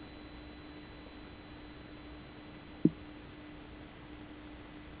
In our recently commisioned audio booth, we can record the sound produced when bladderwort attack.  Below is a raw sound pressure trace of a 'spontaneously' triggered bladder (in the absence of prey).